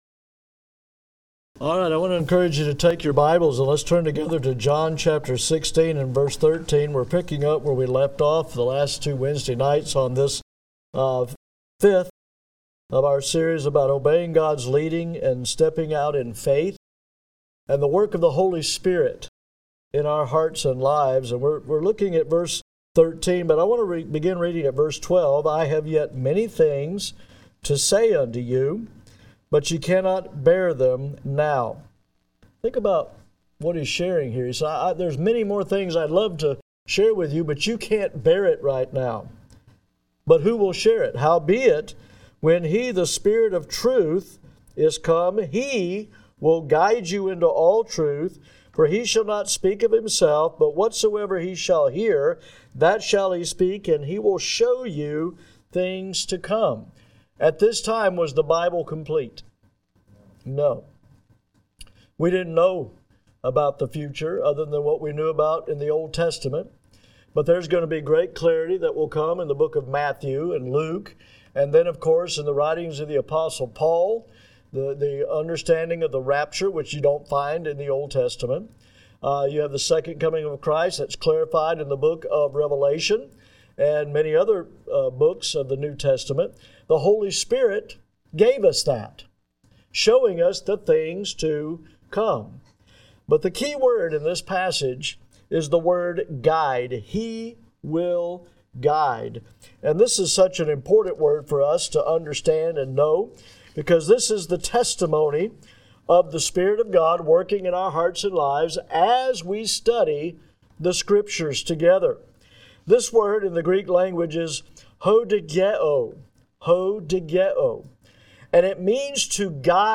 GRACE BIBLE CHURCH Audio Sermons